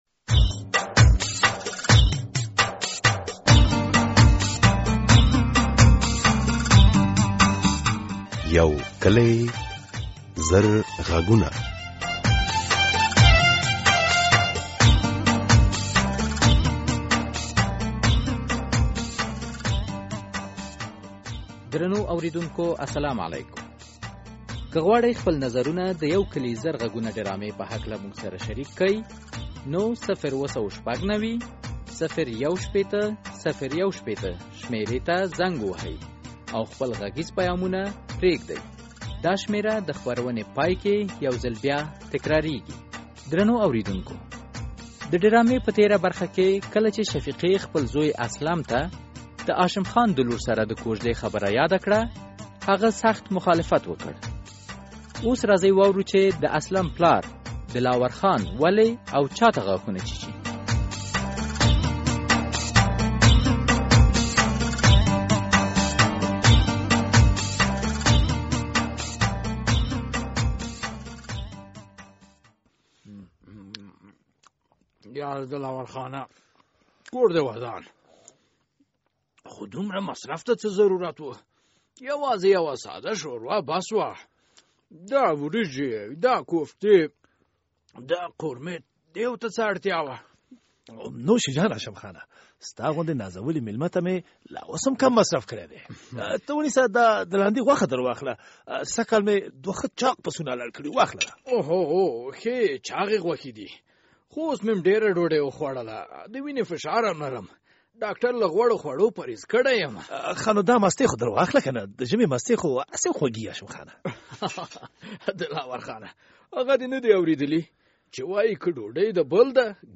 یو کلي او زرغږونه ډرامه هره اوونۍ د دوشنبې په ورځ څلور نیمې بجې له ازادي راډیو خپریږي.